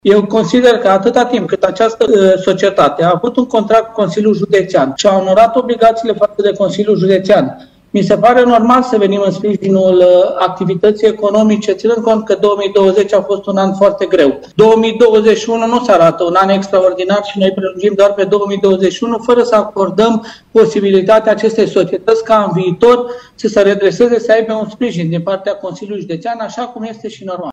La rândul său, Călin Dobra îl contrazice pe succesorul său.